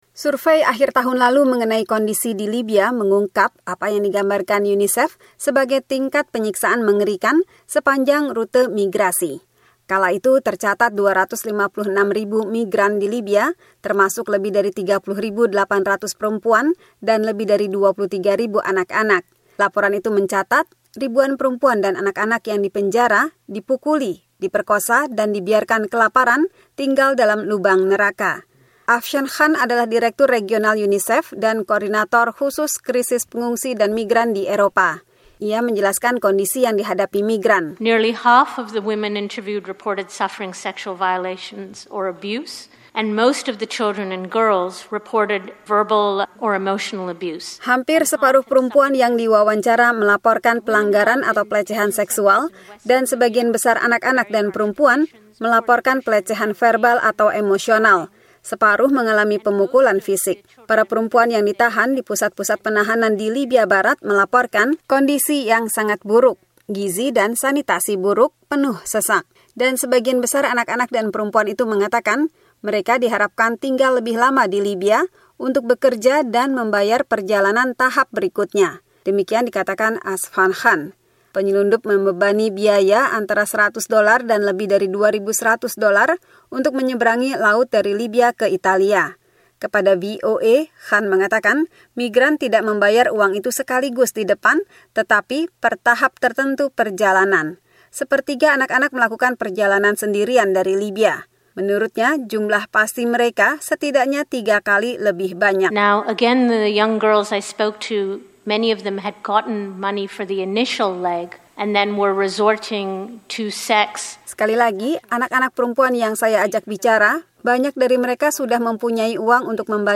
laporan